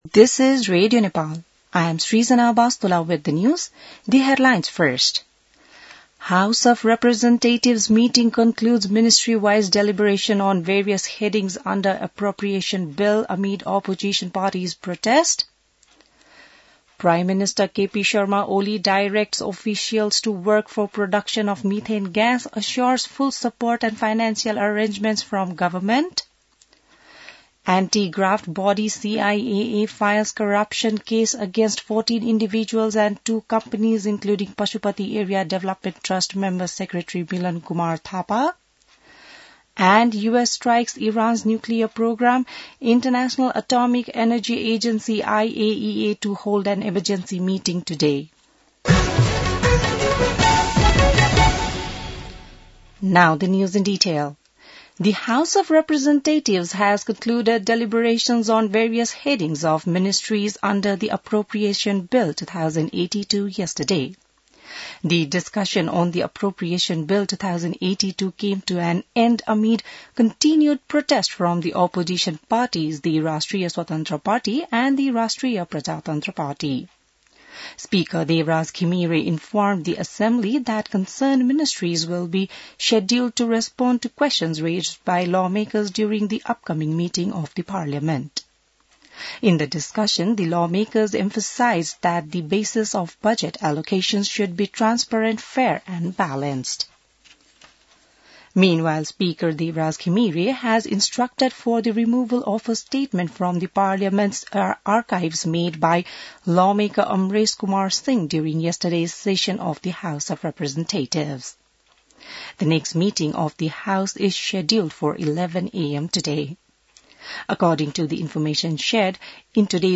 बिहान ८ बजेको अङ्ग्रेजी समाचार : ९ असार , २०८२